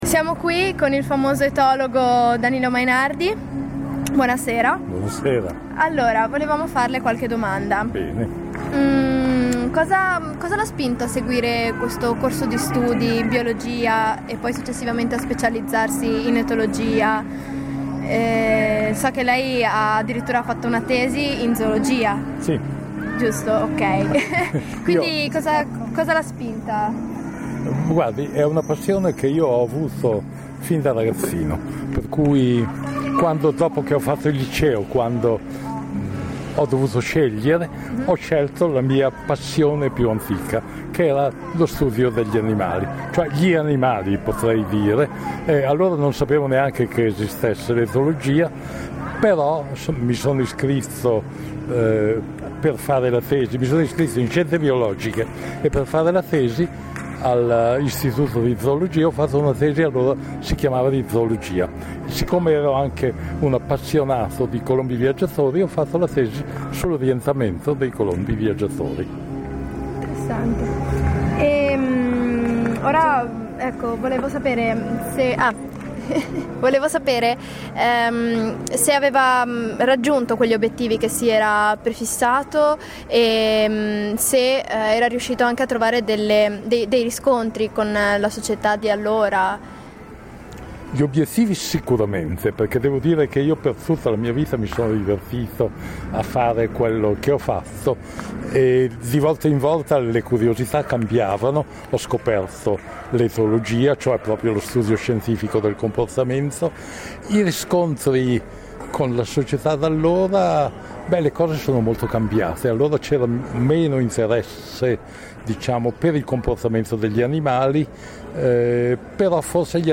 Intervista a Intervista a Danilo Mainardi (etologo)
play_circle_filled Intervista a Intervista a Danilo Mainardi (etologo) Radioweb C.A.G. di Rapallo Etologo intervista del 16/07/2013 Intervista al noto etologo italiano Danilo Mainardi autore di numerosi testi sugli animali, noto anche per numerose apparizioni televisive nonchè presidente onorario della LIPU. Presente a Santa Margherita Ligure per presentare un libro è stato intervistato dalla nostra redazione.